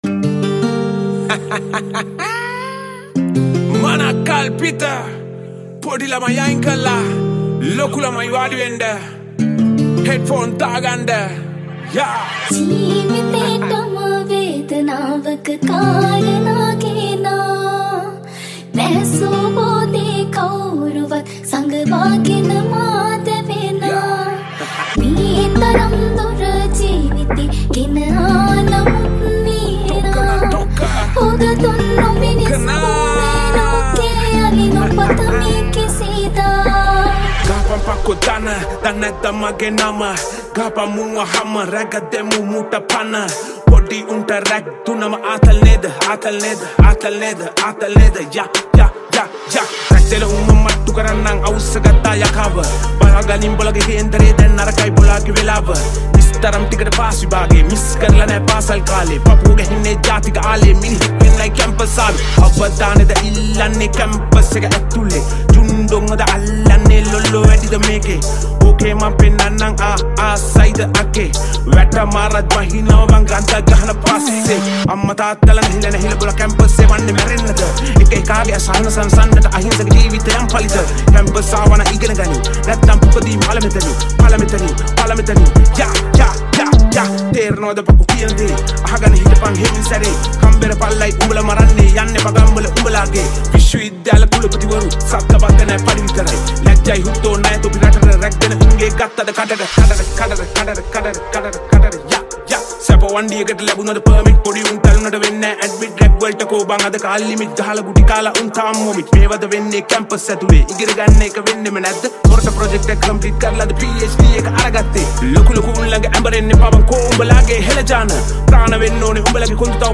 Sinhala Rap